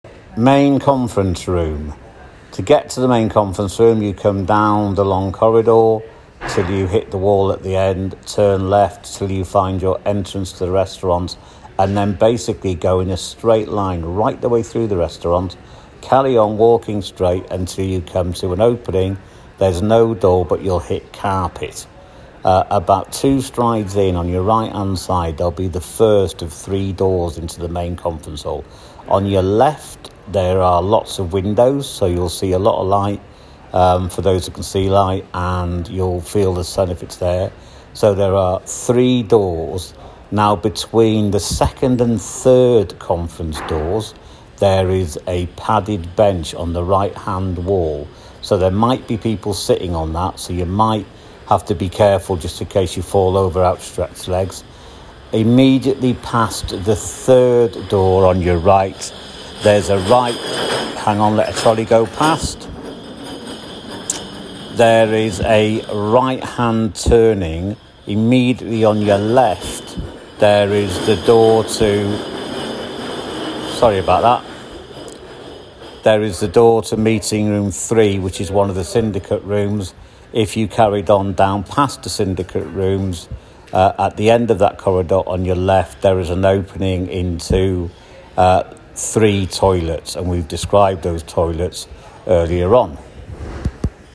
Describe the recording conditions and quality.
We have put these audio files together to help you navigate your way around Conference Aston. They are not slick, but we hope you find them useful.